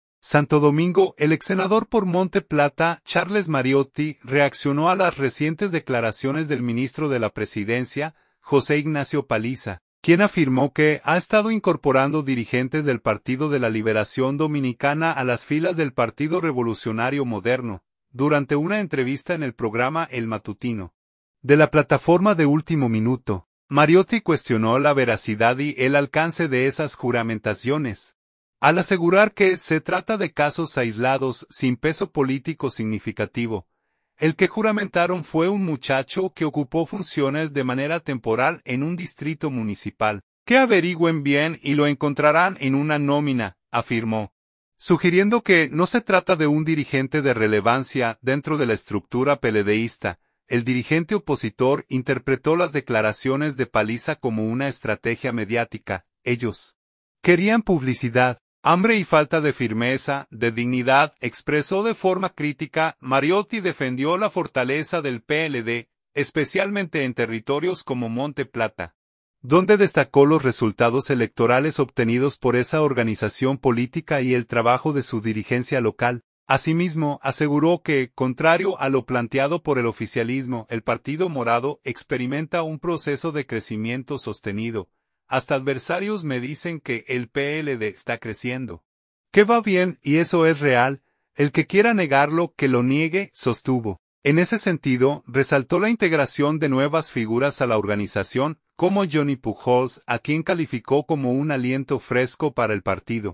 Durante una entrevista en el programa El Matutino, de la plataforma De Último Minuto, Mariotti cuestionó la veracidad y el alcance de esas juramentaciones, al asegurar que se trata de casos aislados sin peso político significativo.